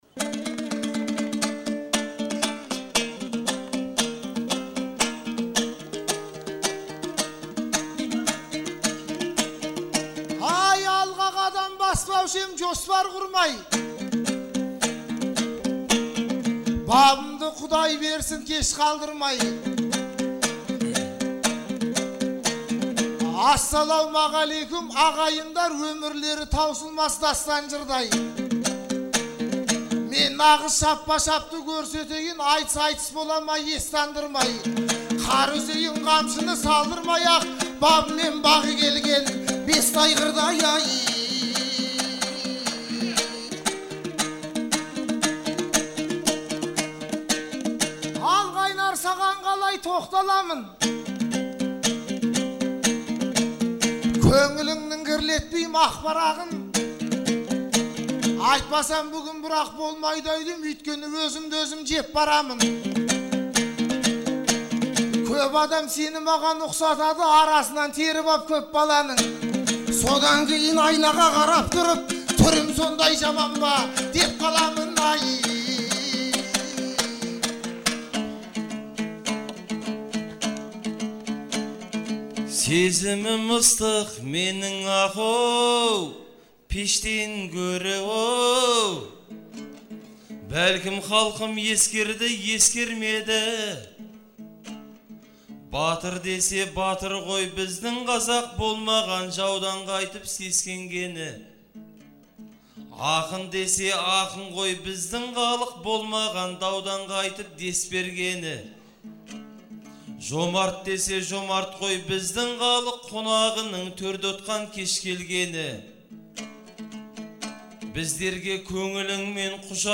Наурыздың 15-і мен 16-сы күні Шымкент қаласында «Наурыз айтысы» өтті.